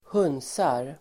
Ladda ner uttalet
Uttal: [²h'un:sar]